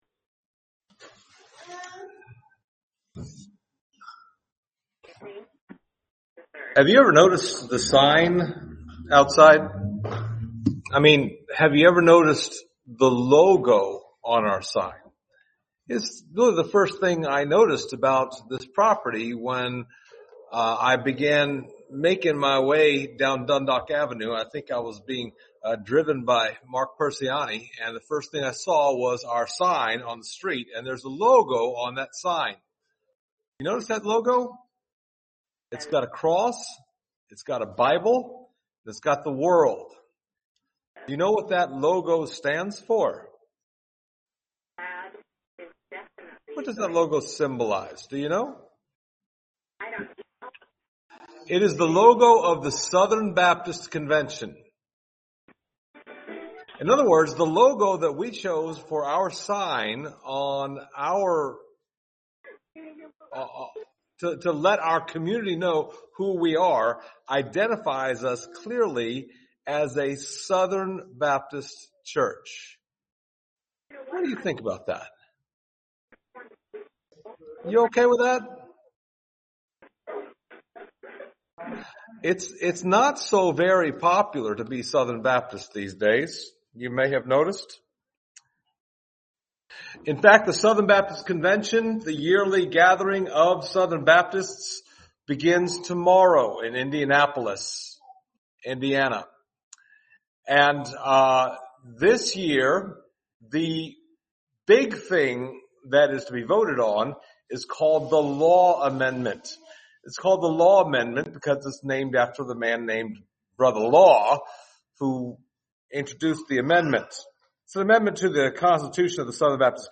Passage: Psalm 8 Service Type: Sunday Morning